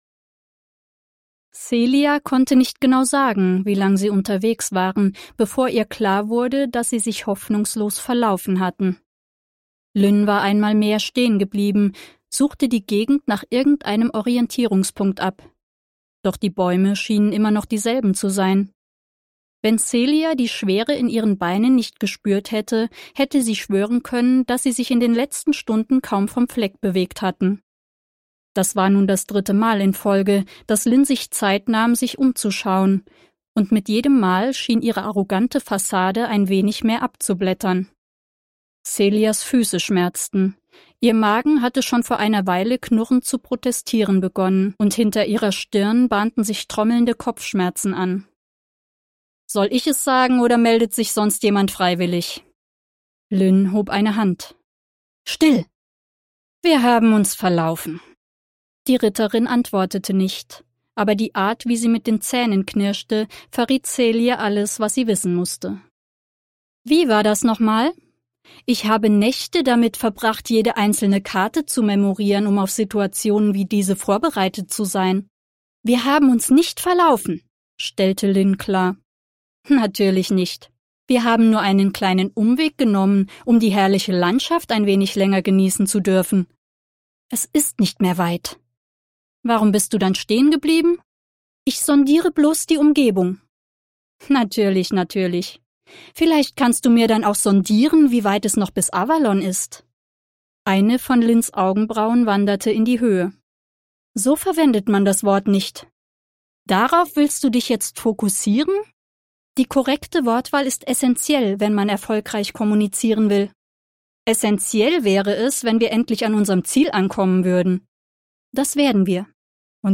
• Top-Studioqualität und professioneller Schnitt
Hörbuch